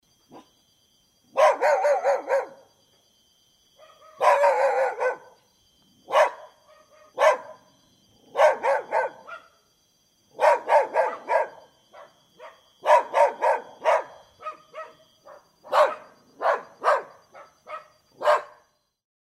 Звуки собак, лая, рычания
Ночной лай деревенских собак